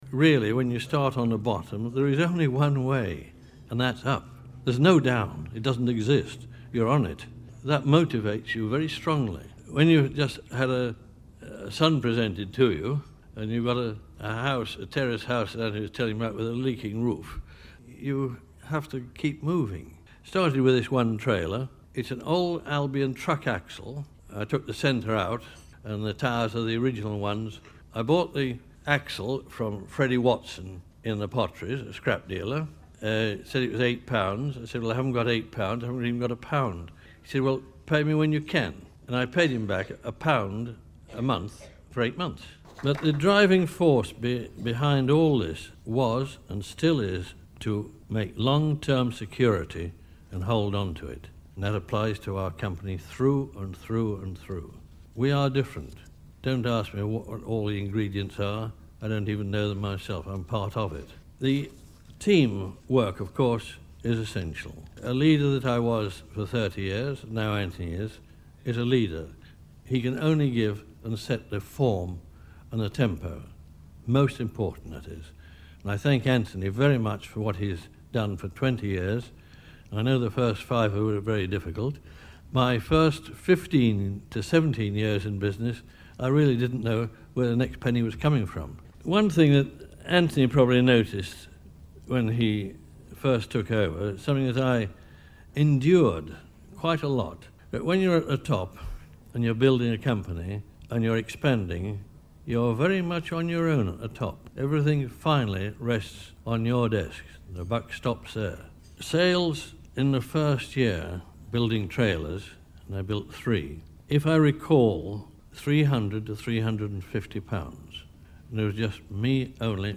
(Broadcast on BBC Radio Stoke, 23rd October 2015)